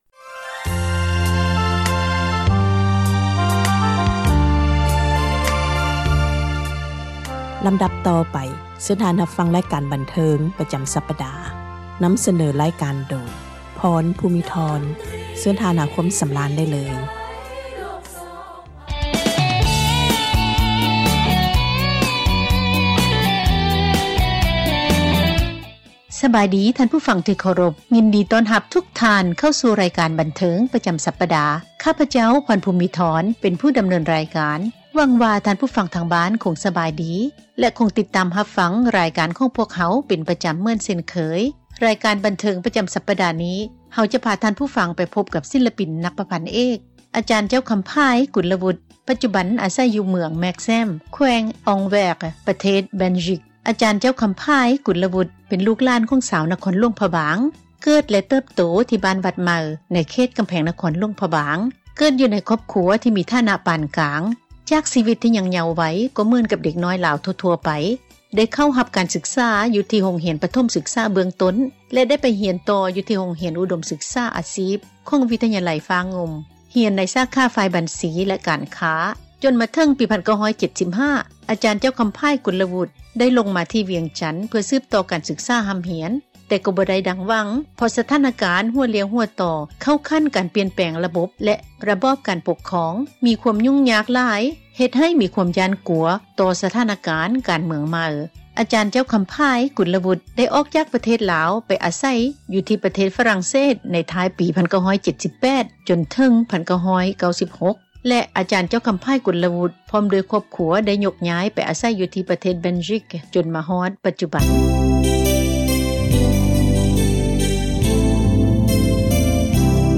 ສັມພາດ ສິລປິນ ນັກຮ້ອງ ນັກປະພັນເອກ